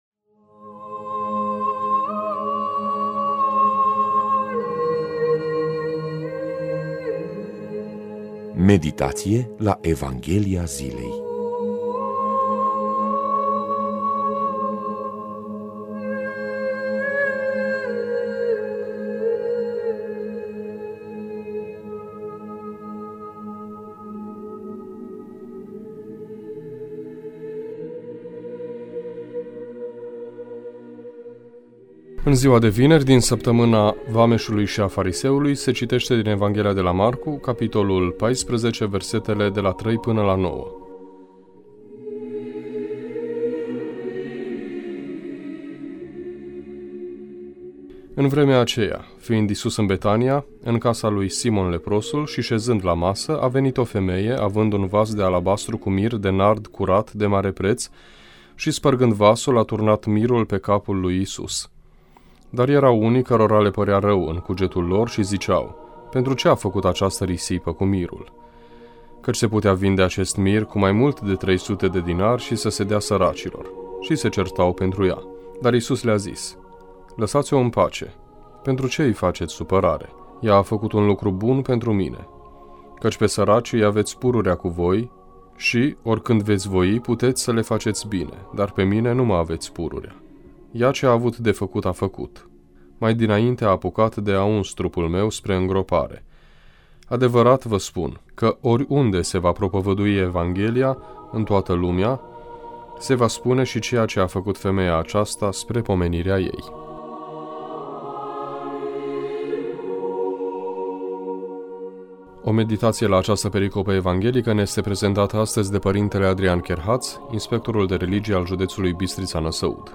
Meditație la Evanghelia zilei Ce risipim și ce câștigăm?